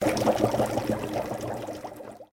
bubble.ogg